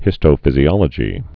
(hĭstō-fĭzē-ŏlə-jē)